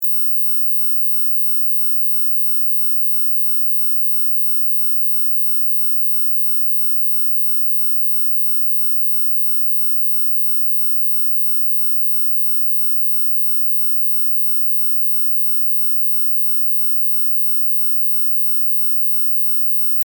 Sonnerie que je n'entend pas (plus), harmoniques du 660 Hz, à partir du 25°, soit 16 500 Hz, au format MP3.